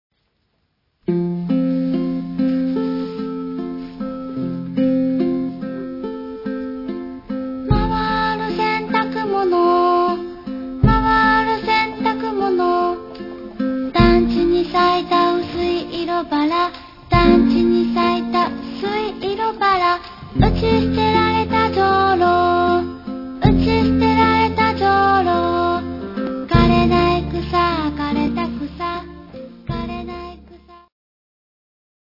単純なメロディーに合わせて時々ドキッとするような歌詞が歌われる。
スタジオ録音による全14曲入り。